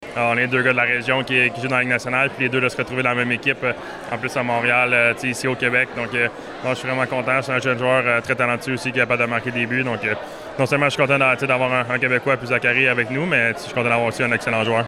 Présent lors du dévoilement de la campagne de financement d’Entraide Bécancour jeudi, le cerbère n’a pas hésité à faire l’éloge du nouvel attaquant du Tricolore.